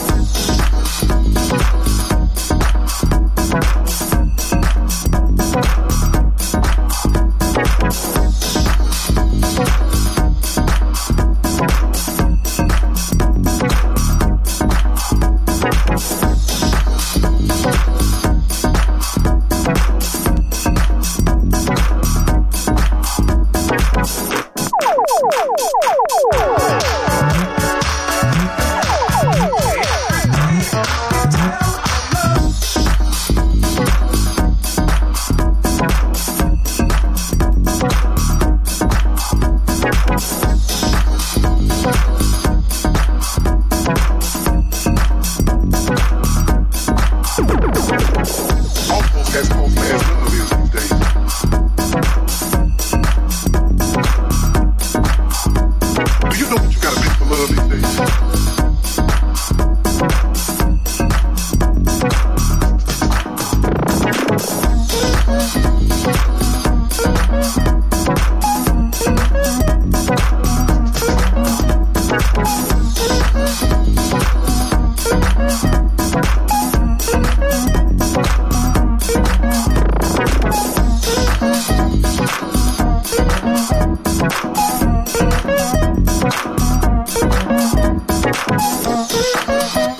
# NU-DISCO / RE-EDIT